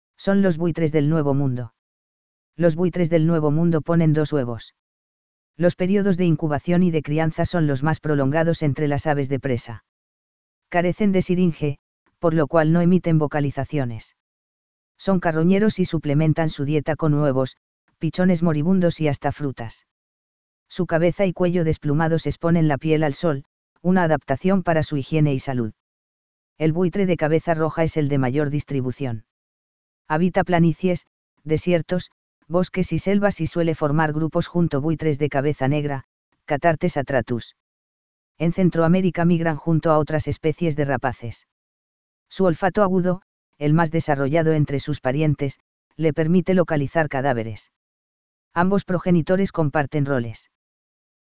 Cathartes aura ruficollis - Cuervo de cabeza roja
Carecen de siringe, por lo cual no emiten vocalizaciones.